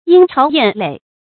莺巢燕垒 yīng cháo yàn lěi
莺巢燕垒发音